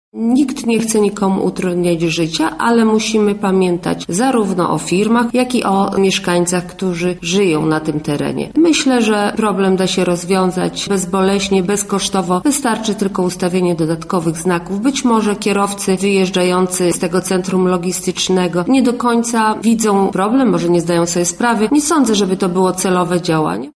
O proponowanym rozwiązaniu problemu mówi Wiceprzewodnicząca Rady Miasta Lublin Marta Wcisło: